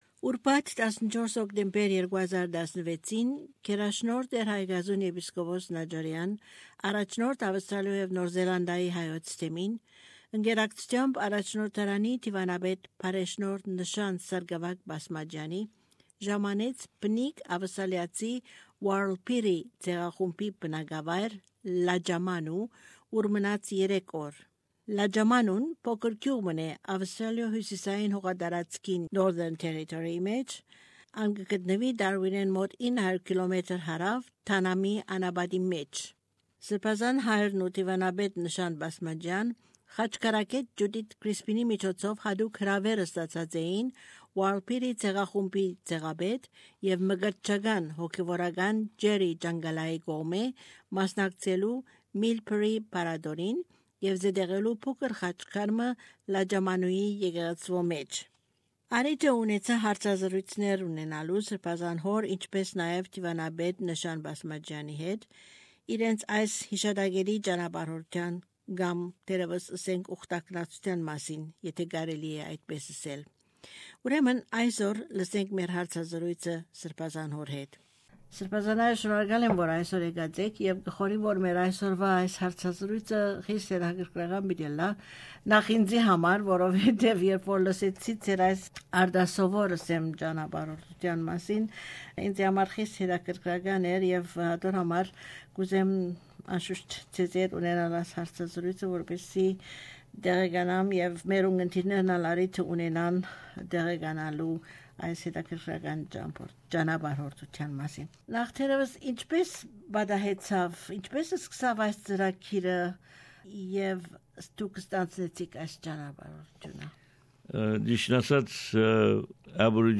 Bishop Haygazoun Najarian - Interview 10/01/2017
Interview with Bishop Haygazoun Najarian, Primate of Armenians in Australia and New Zealand about his visit to the Warlpiri tribe in Lajamanu, where he blessed a 'Khatchkar'.